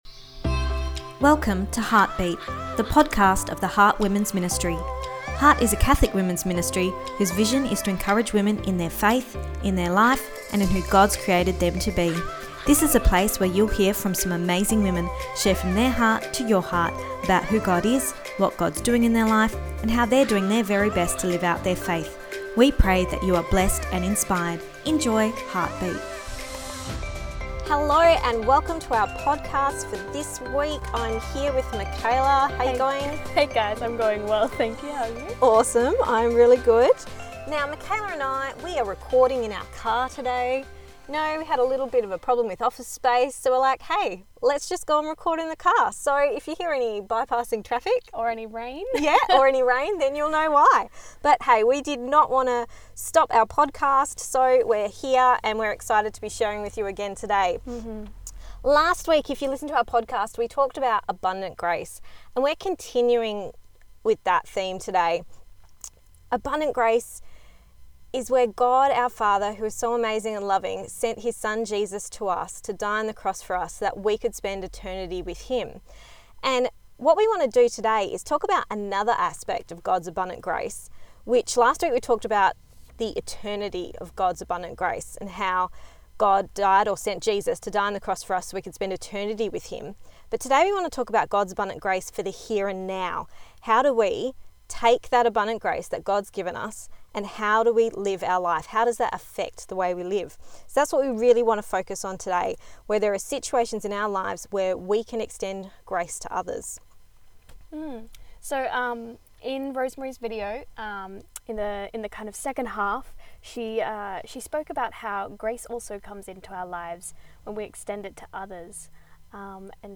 The conversation continues about God’s abundant grace. His grace is so overflowing that there is so much more to say!